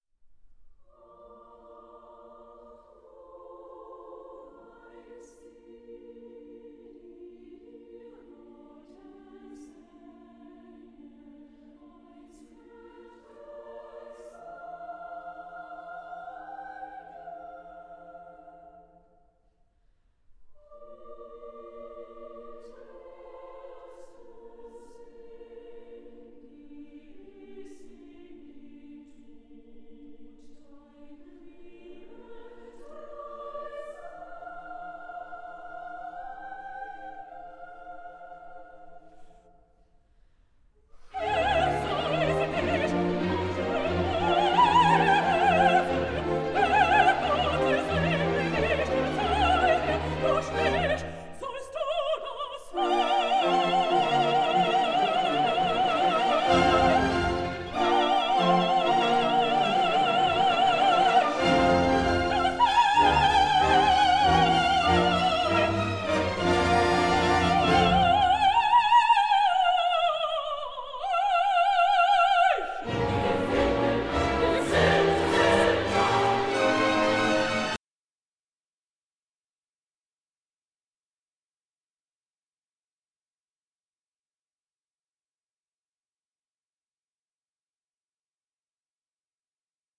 5. Duett